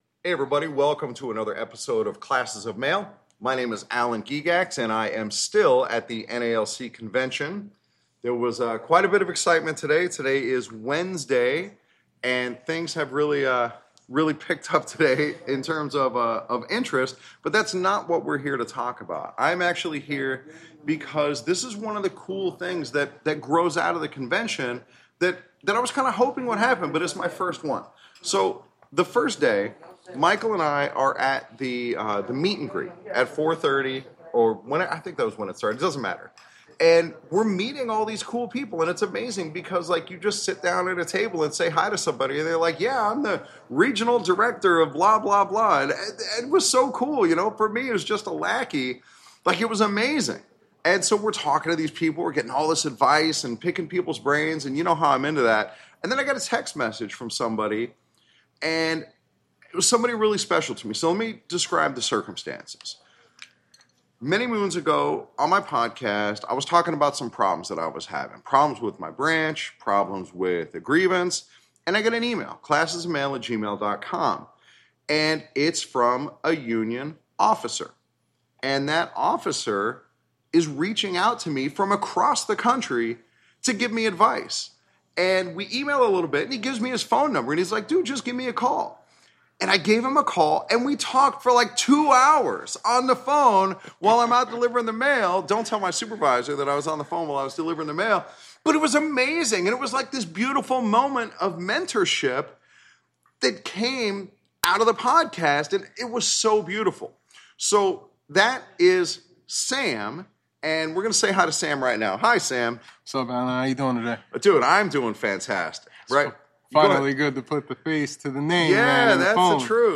Some of the best minds in the NALC are at the national convention, and I was lucky enough to have two of them on the podcast. In this episode, we will learn A TON about handling grievances, making good arguments, and making yourself discipline proof. This conversation was truly one of the highlights of the convention for me.